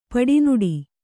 ♪ paḍi nuḍi